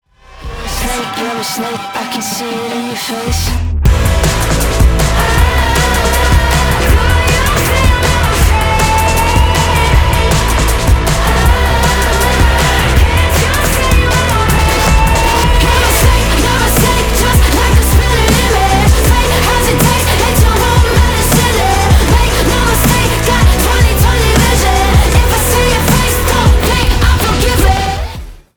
• Качество: 320, Stereo
громкие
женский вокал
саундтреки
Alternative Rock
сильные
Electronic Rock